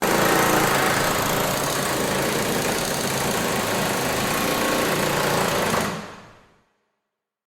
Impact Drill
Impact Drill is a free sfx sound effect available for download in MP3 format.
yt_rx5zV2N3IRA_impact_drill.mp3